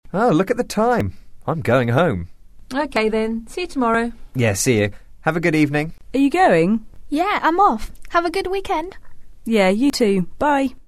english_3_dialogue.mp3